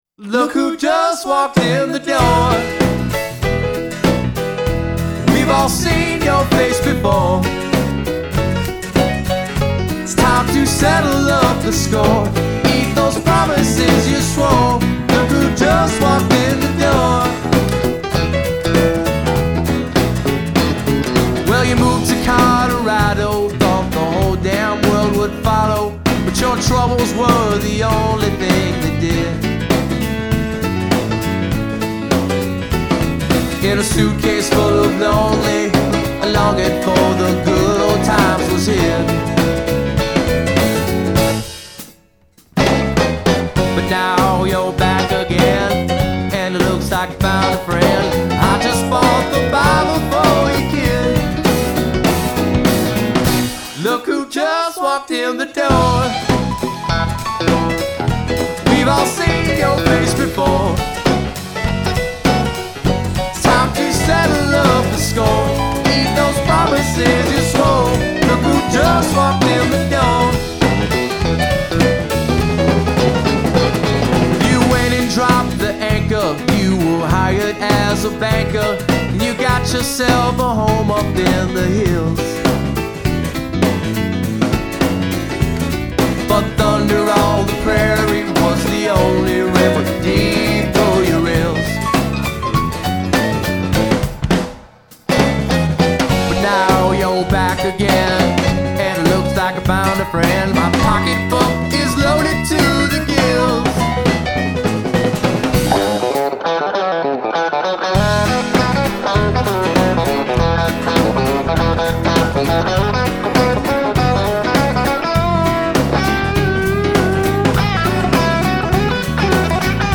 It’s Neo-Classic Rock, in some ways.